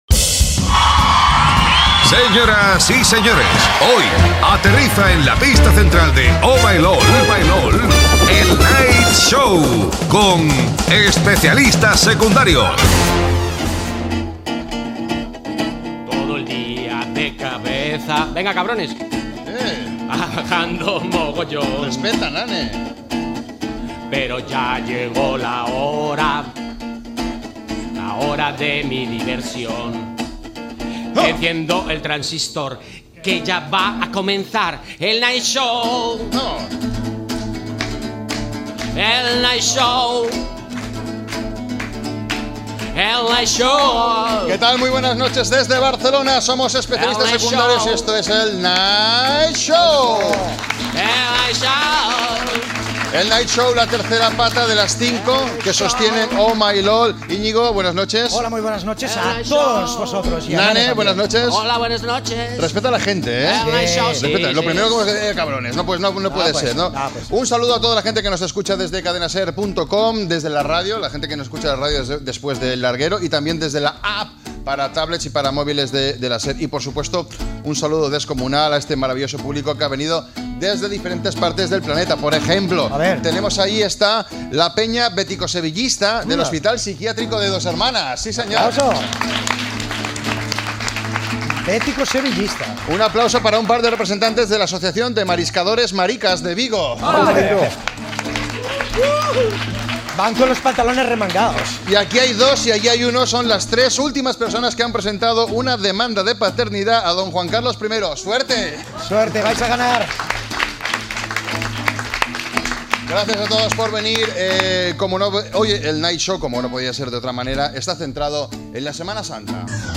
42e04b20c5a06ae8bb625559f4e1c6460d1d5326.mp3 Títol Cadena SER Emissora Ràdio Barcelona Cadena SER Titularitat Privada estatal Nom programa Oh! My LOL (El night show) Descripció Espai fet per Especialistas secundarios. Careta del programa, cançó d'inici interpretada a l'estudi, agraïment a l'audiència que està present, la Setmana Santa (quan se celebra, els consells per no ser robats i la trucada d'un "lladre"). Indicatiu del programa, presentació i entrevista al cuiner Jordi Cruz sobre el seu restaurant, el programa de televisió "Master chef" i la Setmana Santa
Entreteniment